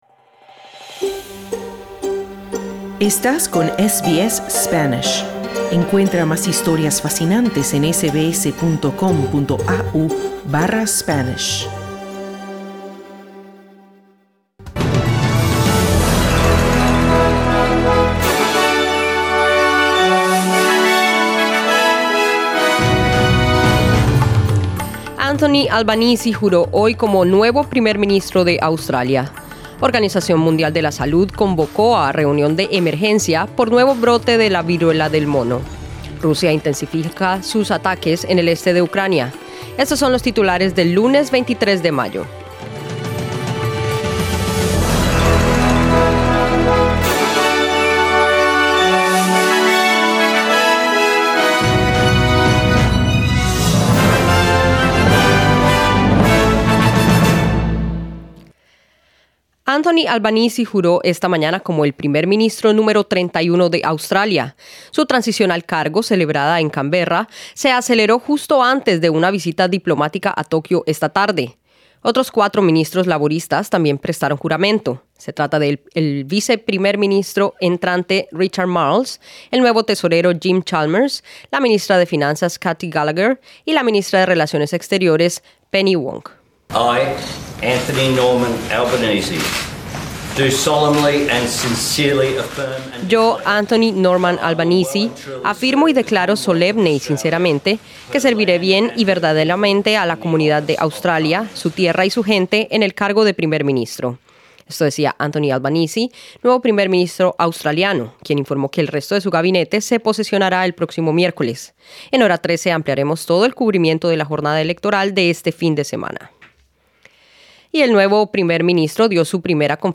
Noticias SBS Spanish | 23 Mayo 2022